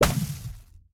Minecraft Version Minecraft Version 1.21.5 Latest Release | Latest Snapshot 1.21.5 / assets / minecraft / sounds / entity / shulker_bullet / hit2.ogg Compare With Compare With Latest Release | Latest Snapshot